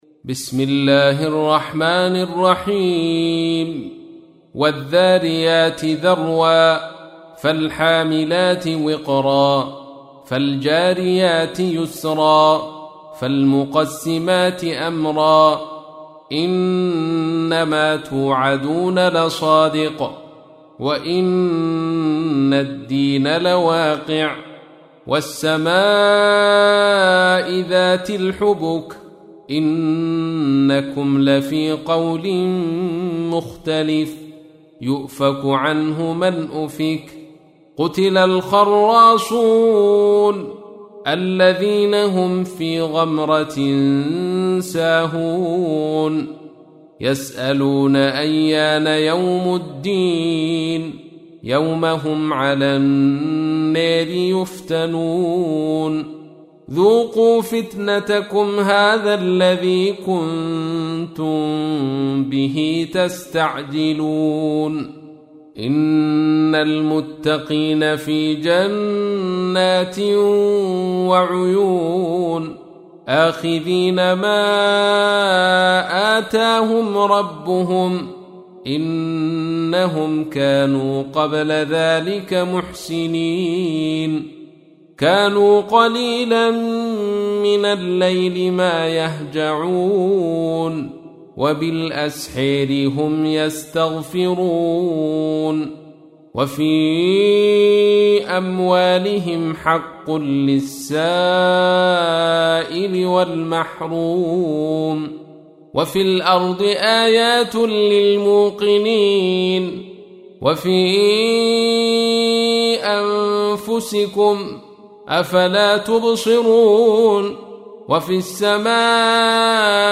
تحميل : 51. سورة الذاريات / القارئ عبد الرشيد صوفي / القرآن الكريم / موقع يا حسين